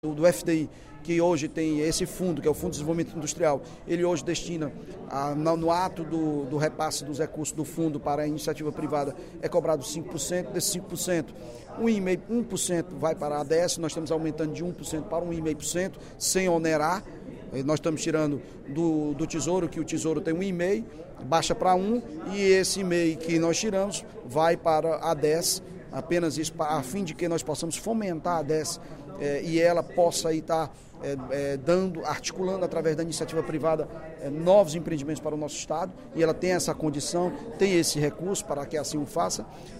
O líder do Governo na Assembleia, deputado Evandro Leitão (PDT), comentou, durante o primeiro expediente da sessão plenária desta quarta-feira (23/09), as quatro mensagens do Poder Executivo.